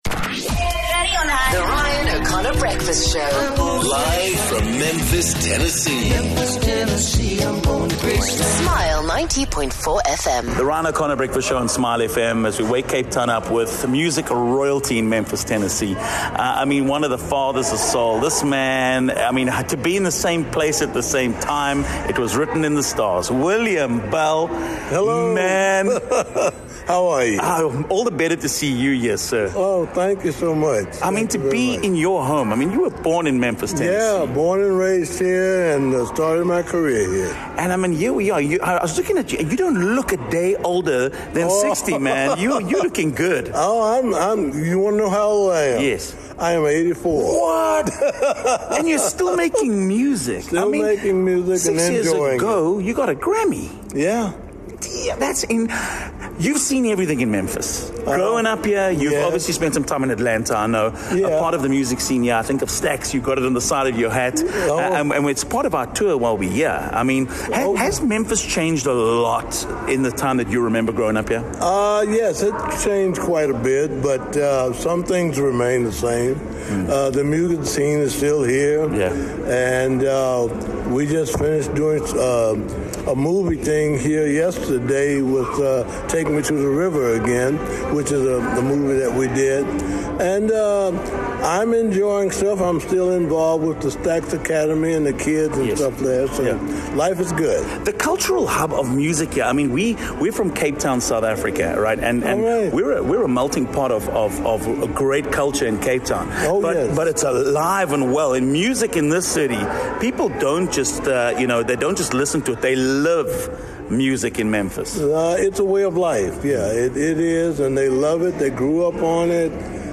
Stax launched the careers of the biggest names in soul music including the likes of s Otis Redding, Isaac Hayes, the Staple Singers, Rufus Thomas, Carla Thomas, Wilson Pickett, Albert King, Eddie Floyd, Jean Knight, Mable John, and William Bell who we got the chance to meet at our hotel.